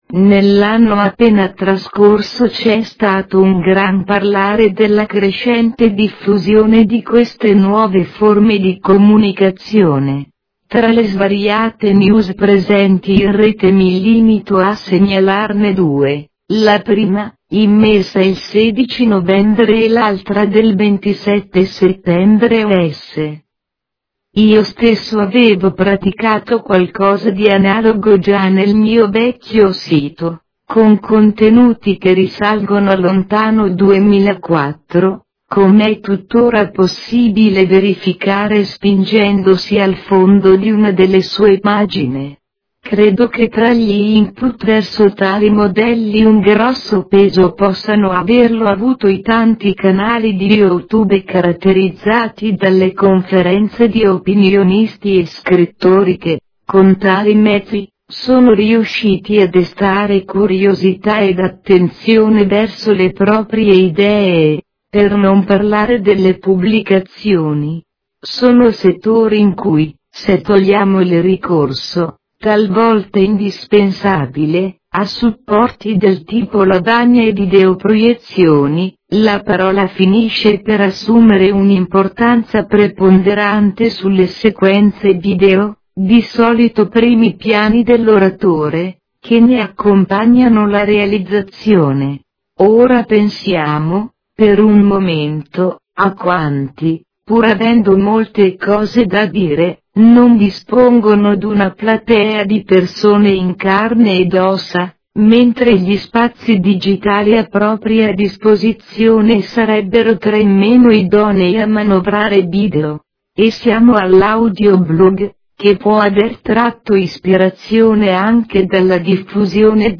versione del testo in voce sintetica. Non bella, ma meno indecente di quelle praticate da alcuni audiogiornali e caratterizzata, fra l'altro, da un tipo di compressione che riduce ad un decimo il peso dell'originale mp3; da 9 Mega a 900 Kb (diciamo che potrebbe tornare utile a quanti hanno una voce che fa schifo, risultano raffreddati o sono afflitti dalla erre moscia).